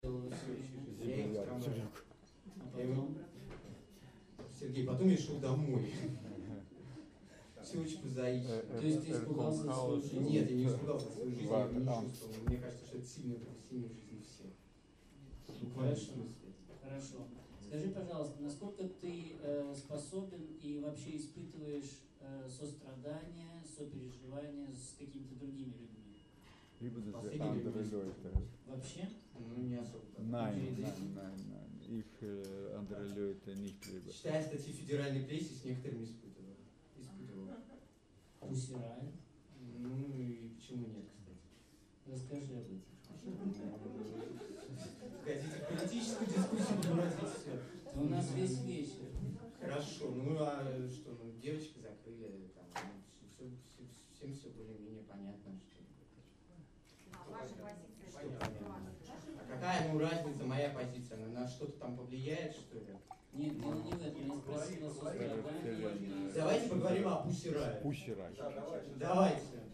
gestern die Lesung eines aufstrebenden Rebellen. er zittert, schleudert seine Gedichte heraus, blinzelt mit geschlossenen Augen wie ein Maulfwurf aus der Grube seiner Eitelkeit in die Luft und frisst das Mikrofon. seine Gedichte scheinen düster, ein wenig gewaltsam, aber treffsicher, wenn auch ein wenig banal. allein, dass sich da jemand heraus nimmt, nicht nach dem klassischen Bild des Dichters zu funktionieren (das hier so lebendig ist), verstörte die Gäste und den Moderator. anstatt ihm offen zu begegnen, wollte man mit Gewalt ein Konzept aus ihm herausquetschen, seine private Folie mit derjenigen abgleichen, die im Kopf bereits implantiert war als Norm. er wehrte ab und es wurde ihm leicht gemacht.